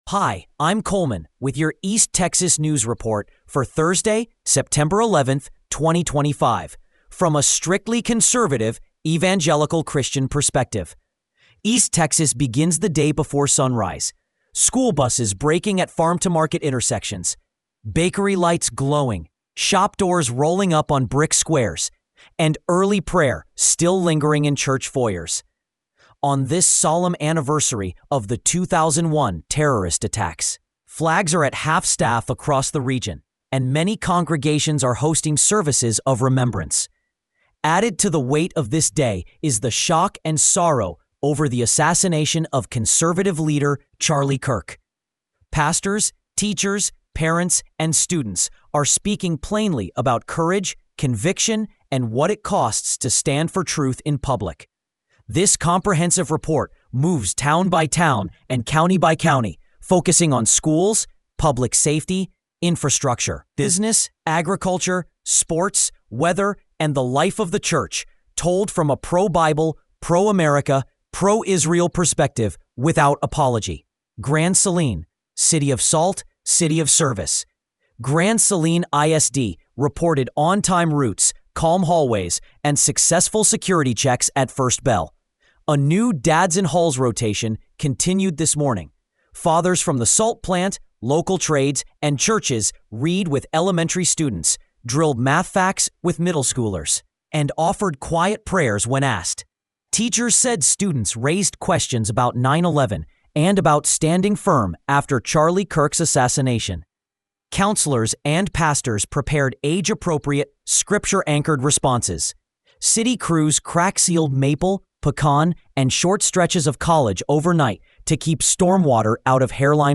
East Texas News Report for Thursday, September 11, 2025